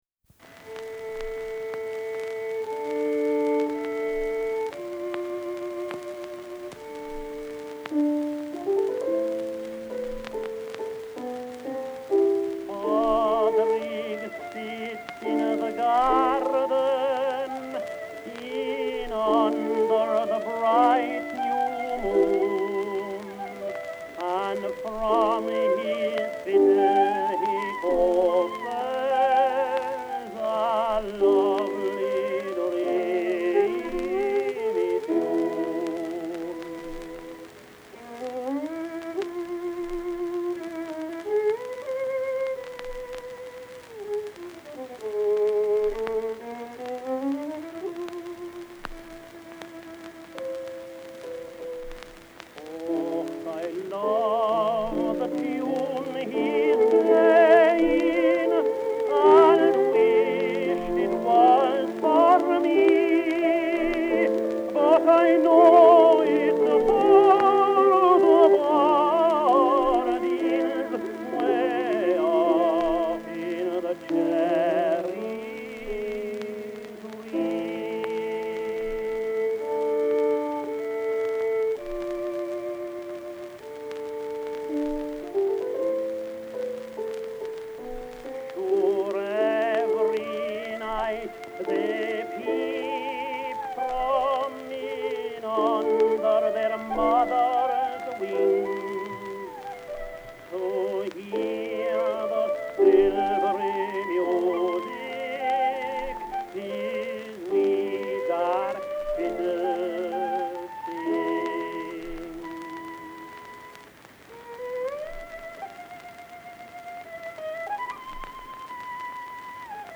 with its mournful violin